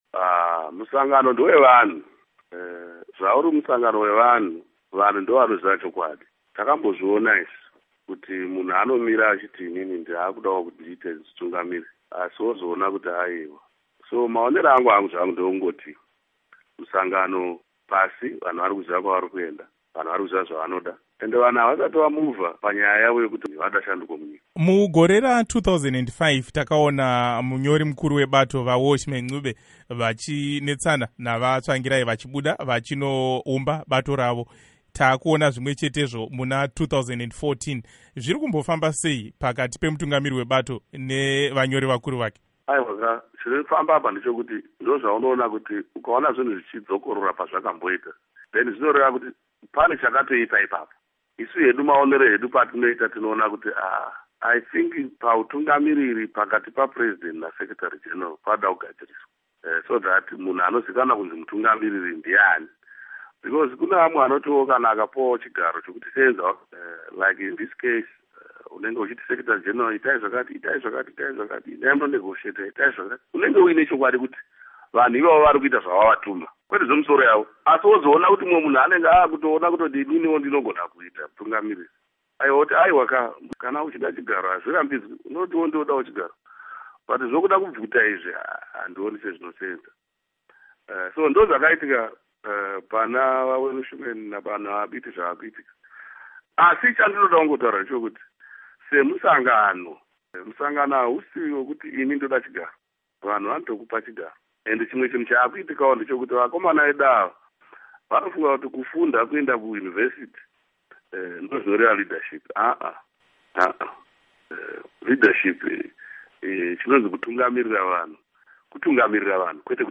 Hurukuro naVaMorgan Tsvangirai